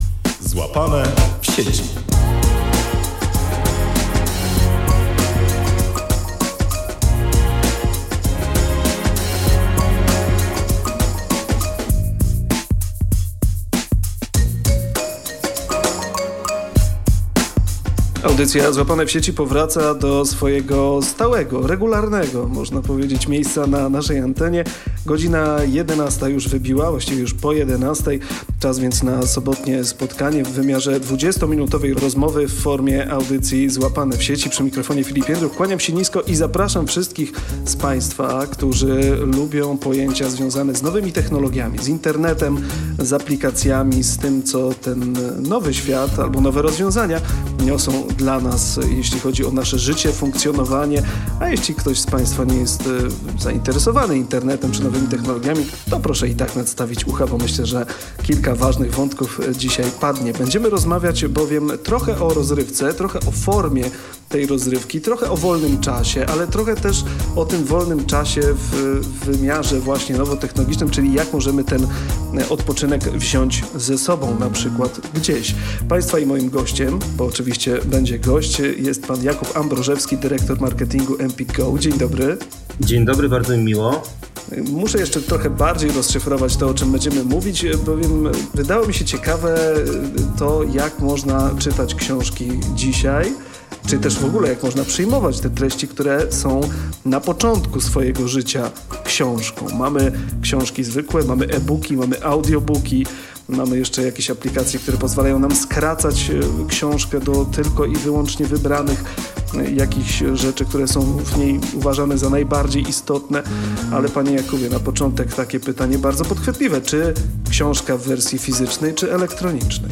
W kolejnym wydaniu audycji „Złapane w Sieci” rozmawiamy o zaletach, wadach i różnicach między książkami i nowoczesnymi formatami.